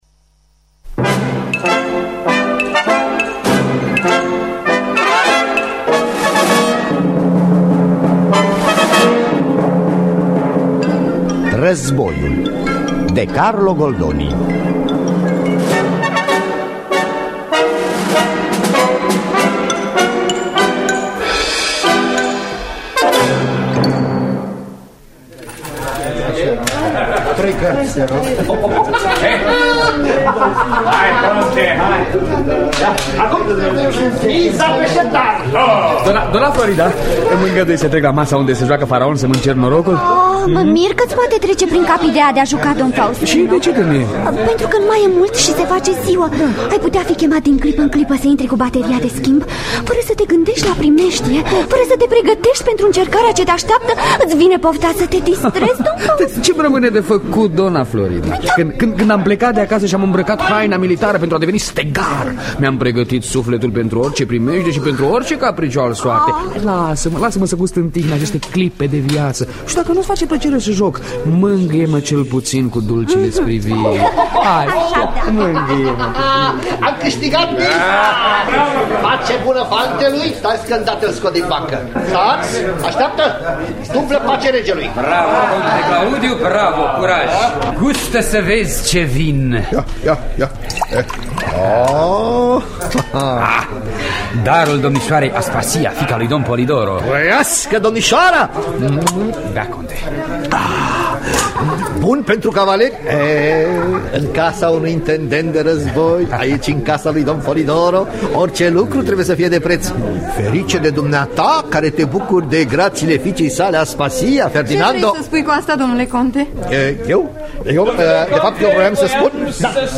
Adaptarea radiofonică
Înregistrare din anul 1979.